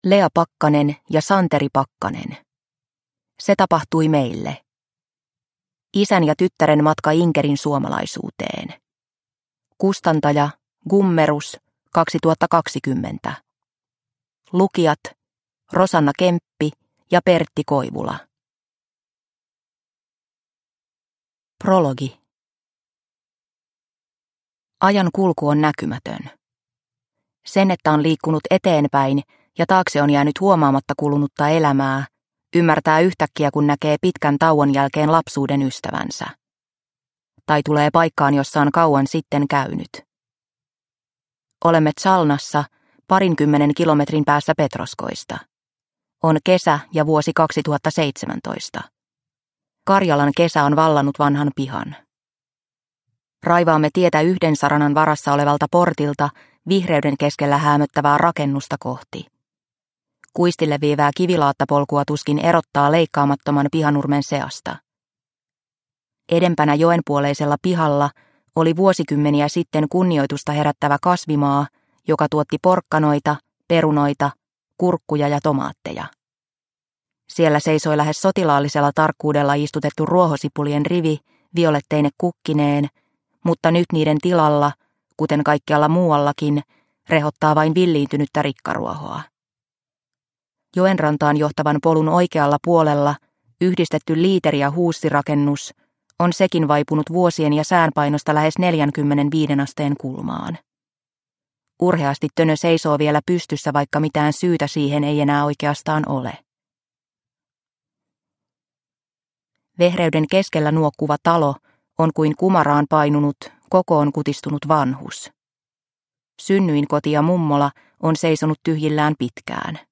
Se tapahtui meille – Ljudbok – Laddas ner